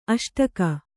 ♪ aṣtaka